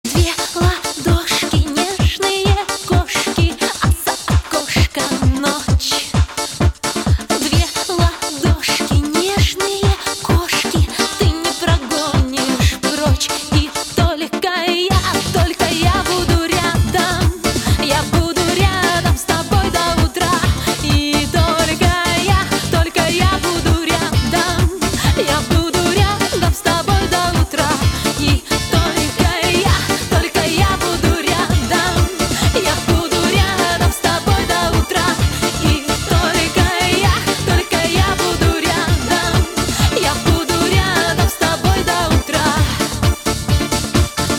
попса